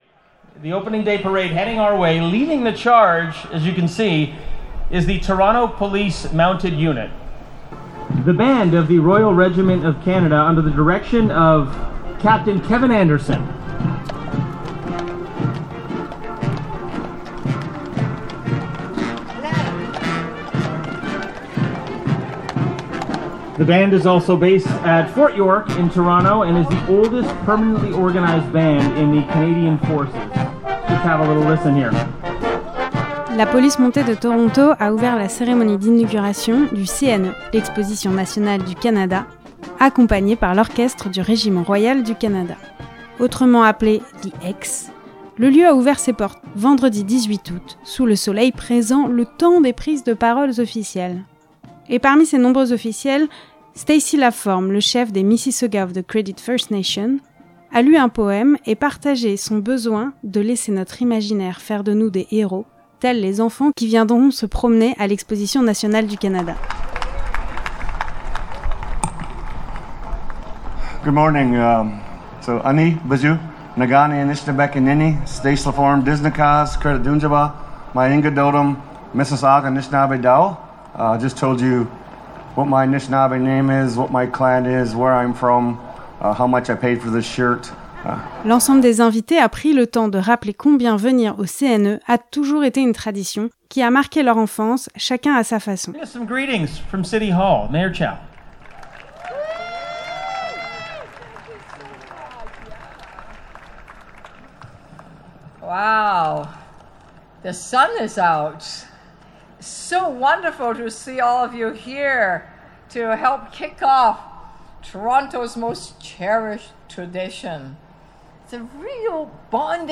L’Exposition nationale du canadienne rouvre ses portes, ce vendredi 18 août jusqu’au 4 septembre. Plusieurs officiels ont pris la parole devant un public de fidèles, heureux de retrouver cette tradition vieille de 144 ans.